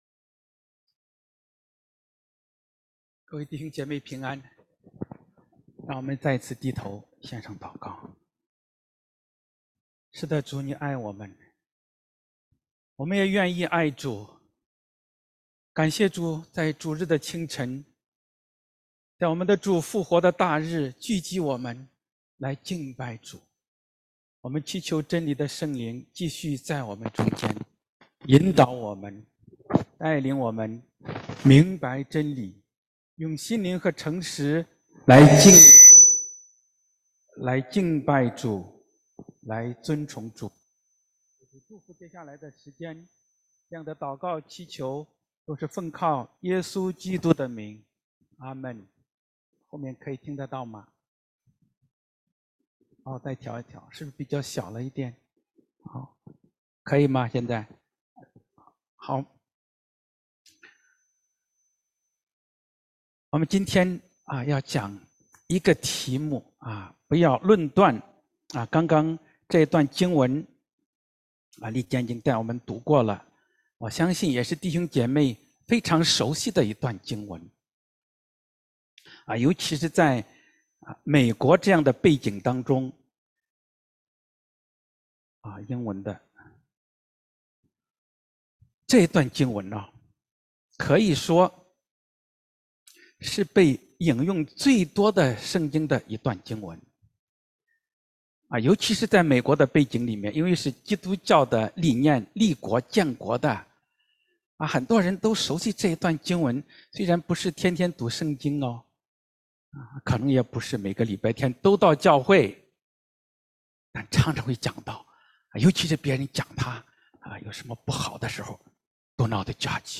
Posted in 福音 ← Newer Sermon Older Sermon →